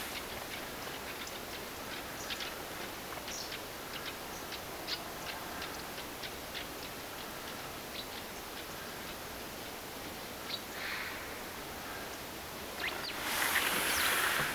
onko tällainen ääntely
pikkuvarpusen huomioääntelyä
onko_tuo_pikkuvarpusen_huomioaantelya.mp3